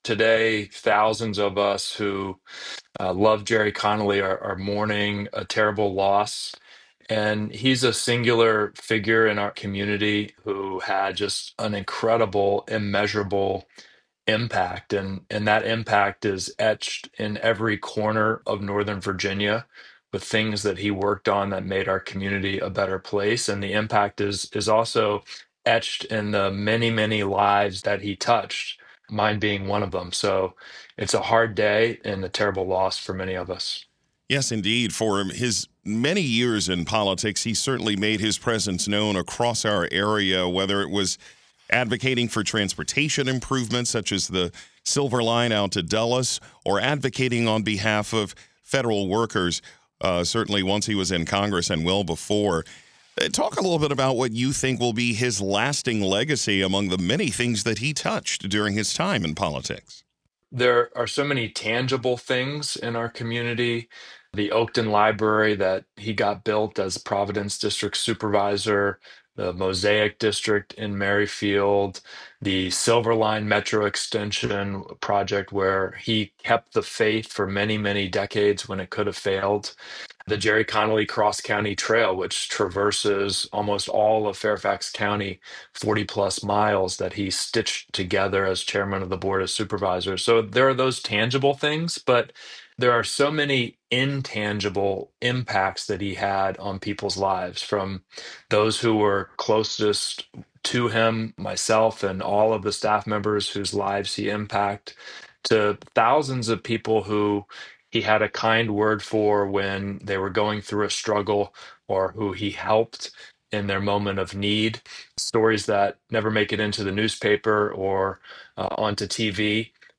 Fairfax Supervisor James Walkinshaw remembers Connolly's impacts — both tangible and intangible